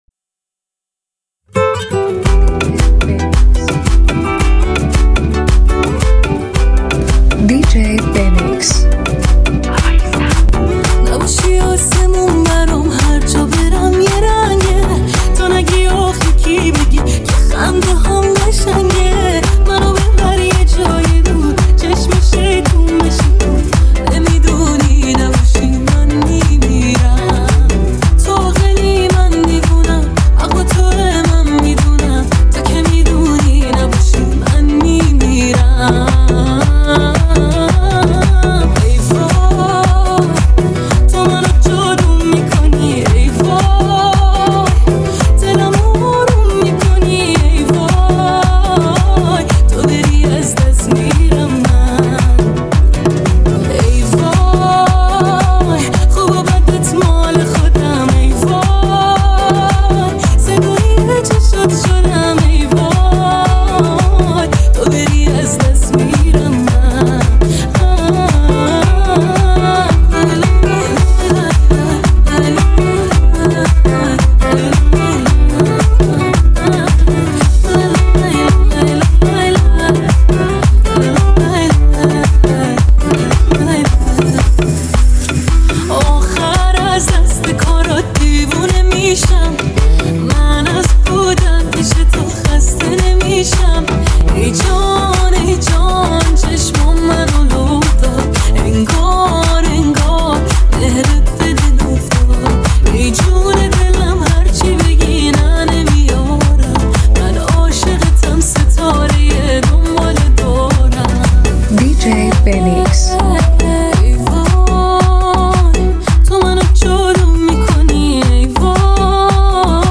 ترکیبی از ملودی‌های عمیق و بیت‌های پرانرژی است